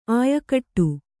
♪ āyakaṭṭu